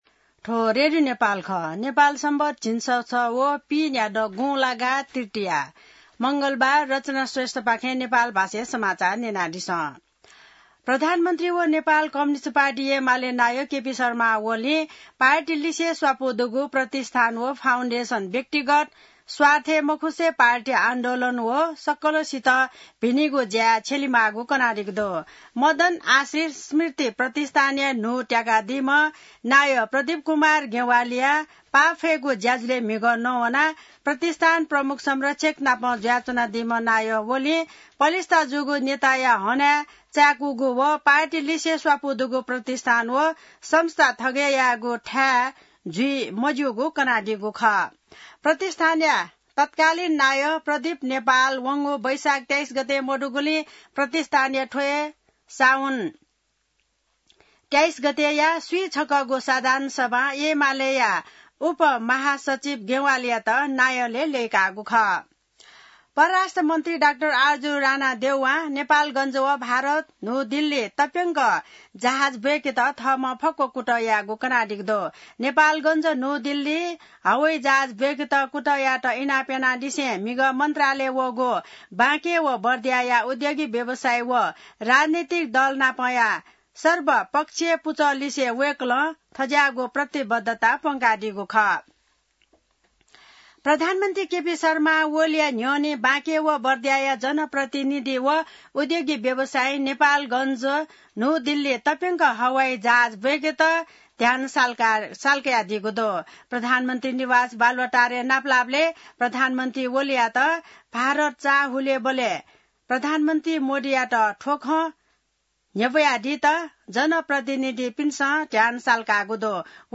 नेपाल भाषामा समाचार : २७ साउन , २०८२